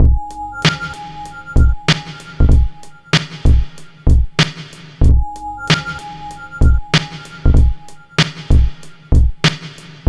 BEAT 2 95 01.wav